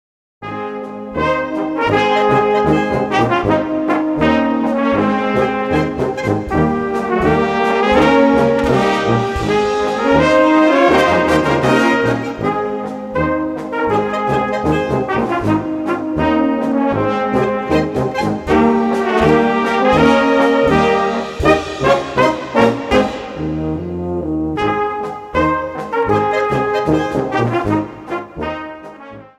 Gattung: Bravour-Polka
Besetzung: Blasorchester